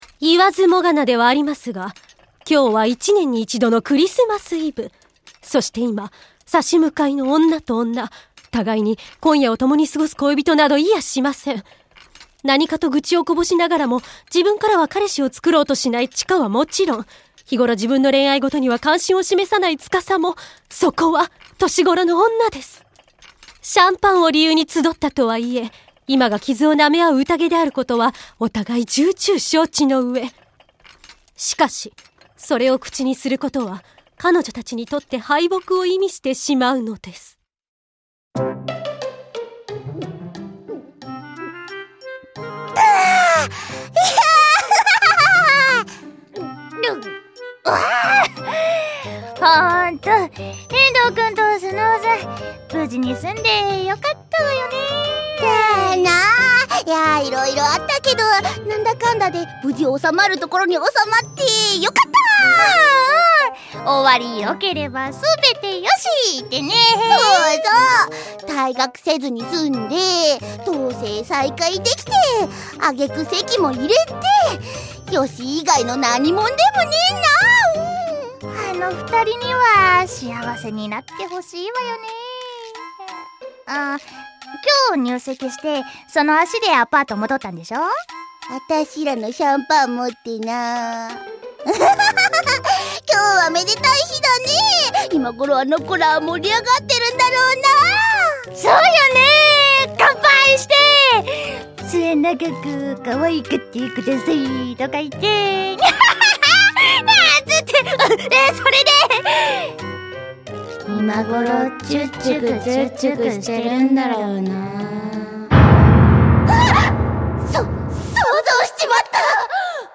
シリウス初のおたのしみ＆ファンディスク的ドラマＣＤがついに発売！